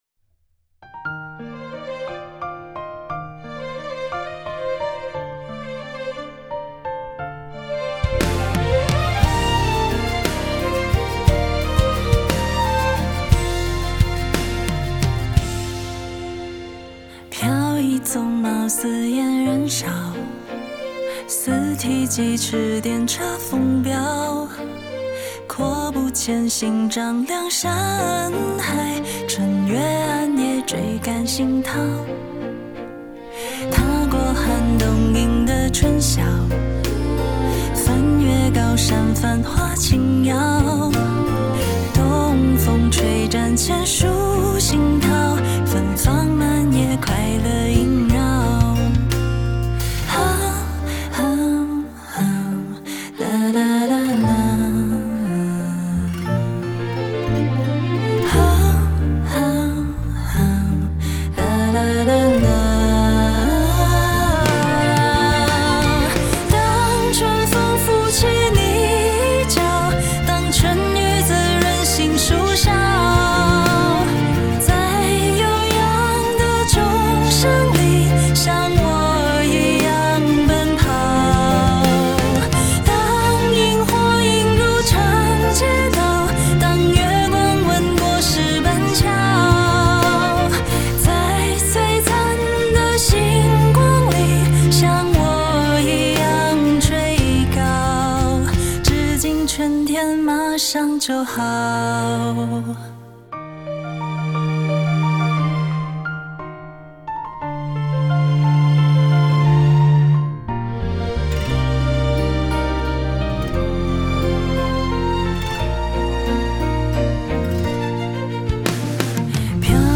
他继而将这些特质转化为具体的音乐语言，如用“持续向前推进的节奏律动”象征奔腾，用“开阔上扬的旋律线条”寓意远志等。
而灵动飘逸且满含暖意的旋律，与歌词的诗意语境相得益彰，将冬去春来的生机娓娓道来。